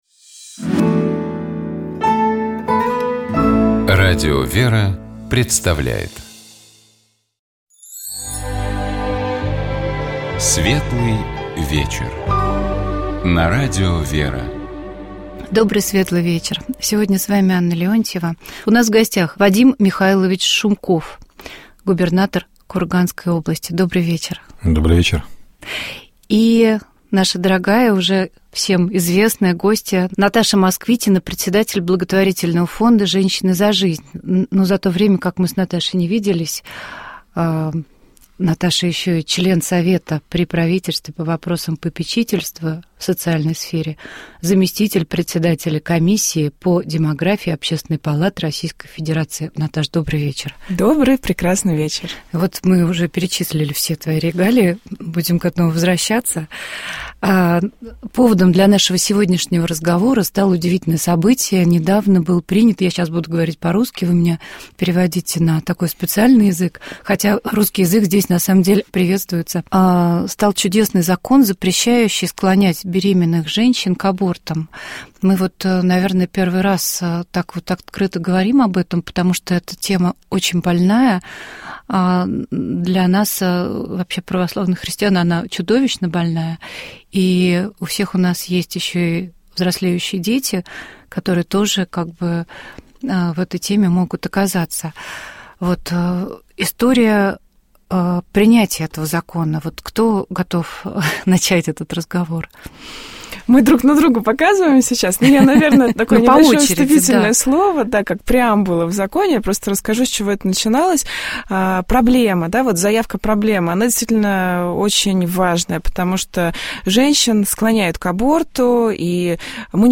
Нашей собеседницей была кандидат искусствоведения, пианистка